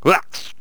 stickfighter_attack6.wav